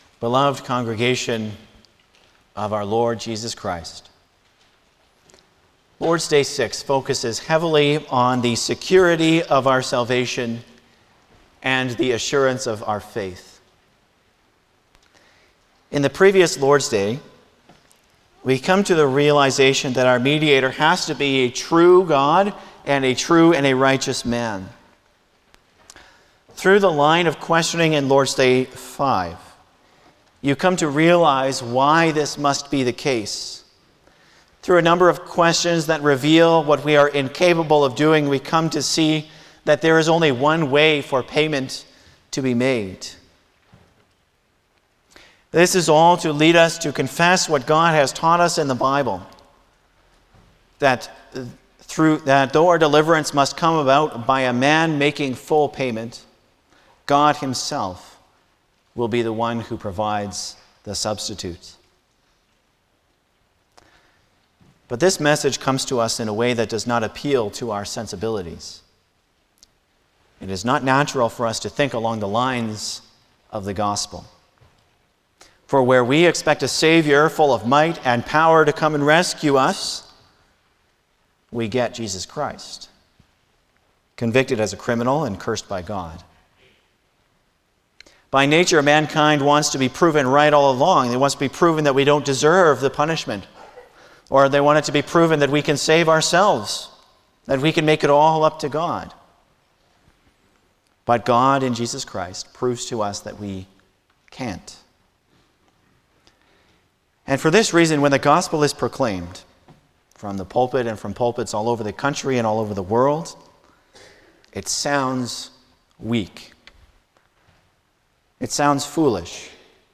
Service Type: Sunday afternoon
08-Sermon.mp3